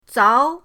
zao2.mp3